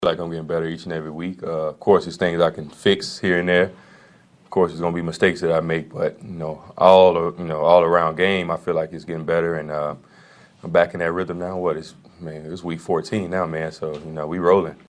Chiefs linebacker Willie Gay says they have to stay focused on playing better.